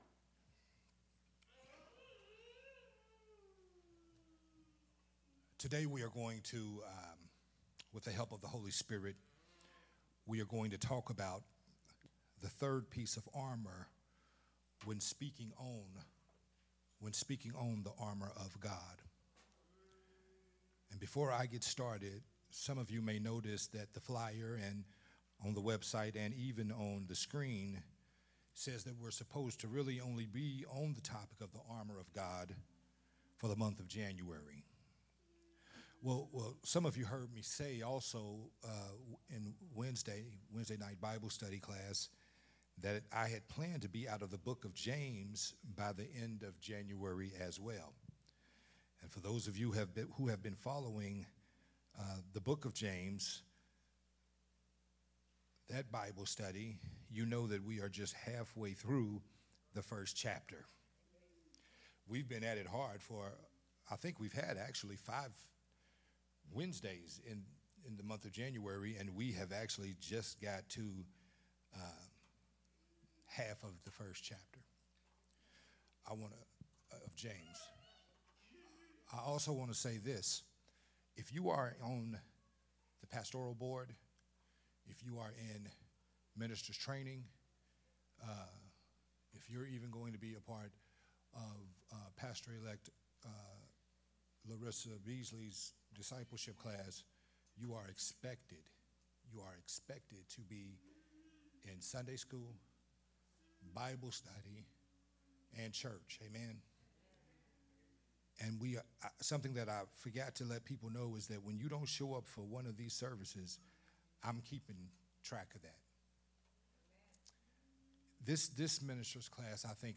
Part 5 of the sermon series “Dress for Success”
recorded at Unity Worship Center on February 4th, 2024.